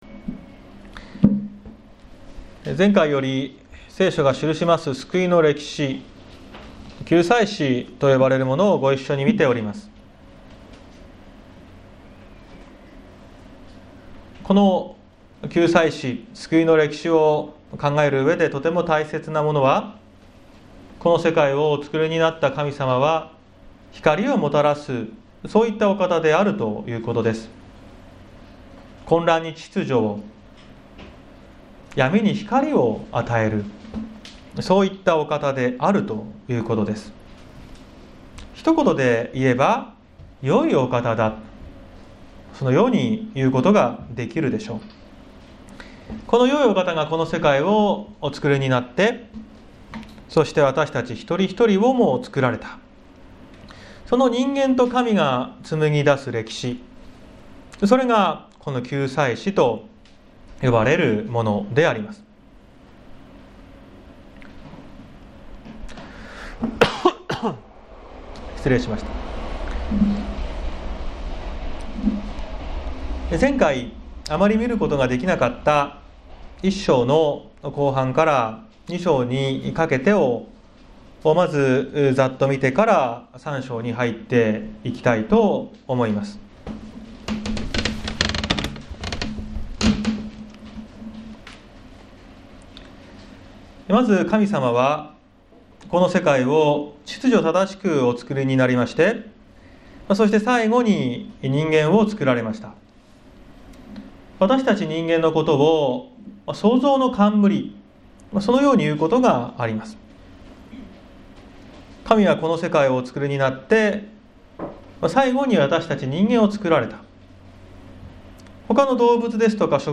2021年09月12日朝の礼拝「悪の敗北」綱島教会
説教アーカイブ。
横浜市港北区大曽根のプロテスタント教会です。